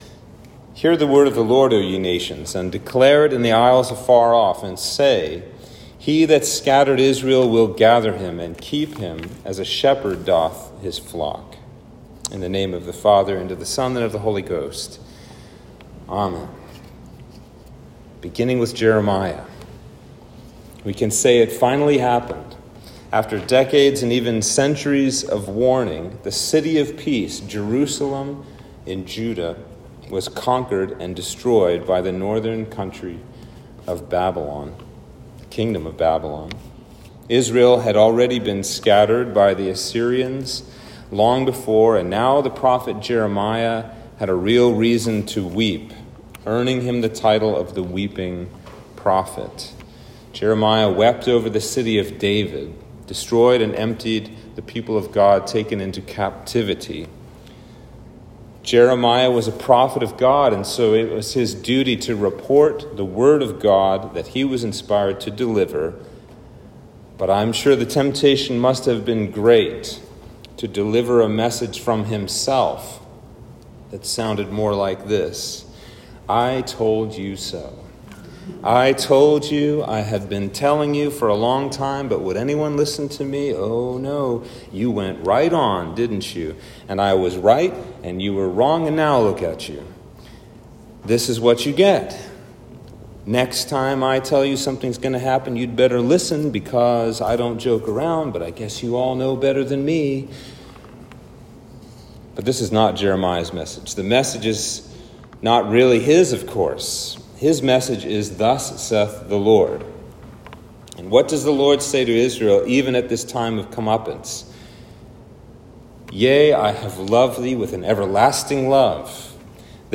Sermon for Trinity 3